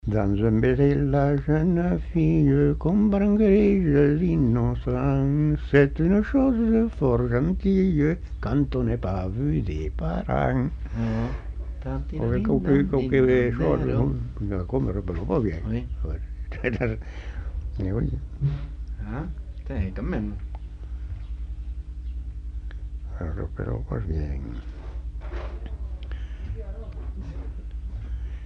Lieu : Simorre
Genre : chant
Effectif : 1
Type de voix : voix d'homme
Production du son : chanté
Danse : scottish